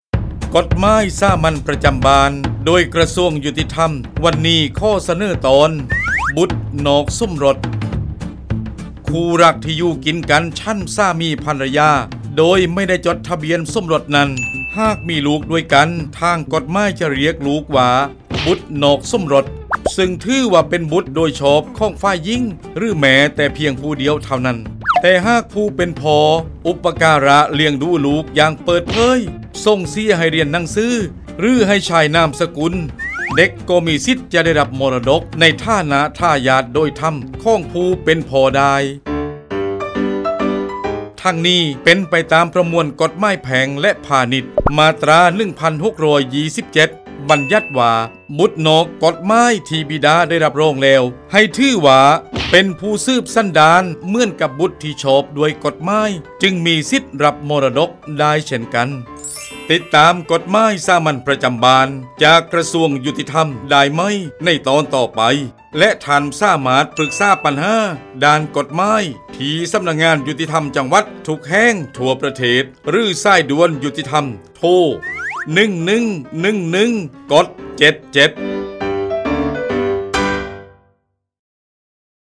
กฎหมายสามัญประจำบ้าน ฉบับภาษาท้องถิ่น ภาคใต้ ตอนบุตรนอกสมรส
ลักษณะของสื่อ :   คลิปเสียง, บรรยาย